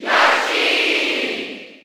Category:Crowd cheers (SSB4) You cannot overwrite this file.
Yoshi_Cheer_German_SSB4.ogg